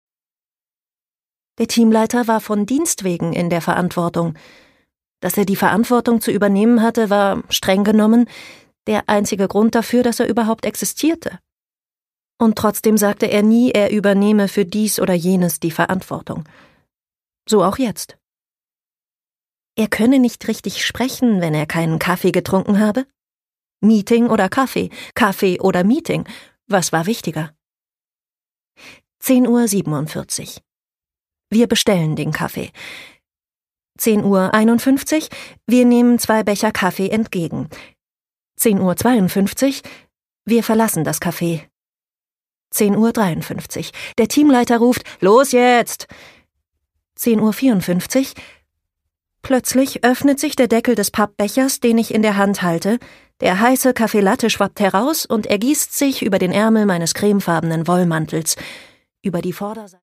Jang Ryujin: Bis zum Mond (Ungekürzte Lesung)
Produkttyp: Hörbuch-Download